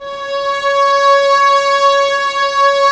Index of /90_sSampleCDs/Roland L-CD702/VOL-1/STR_Vlas Bow FX/STR_Vas Sordino